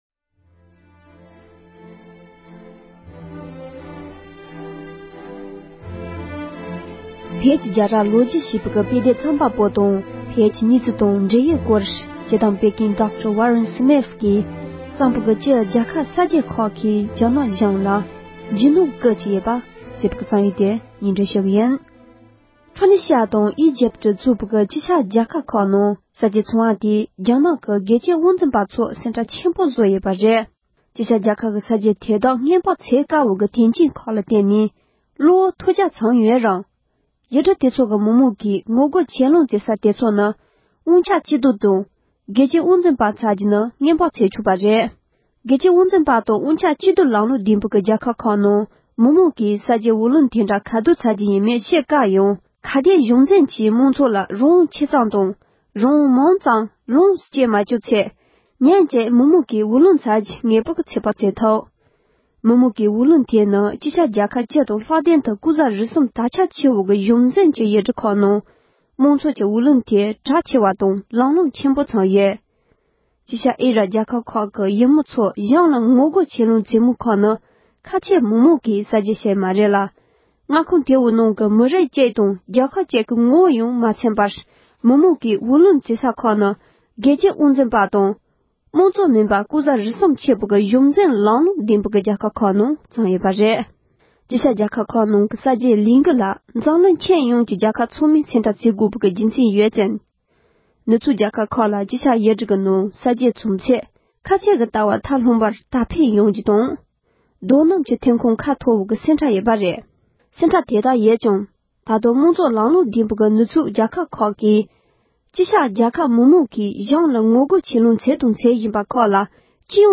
ཕྱོགས་སྒྲིག་དང་སྙན་སྒྲོན་ཞུས་པ་ཞིག་ལ་གསན་རོགས་ཞུ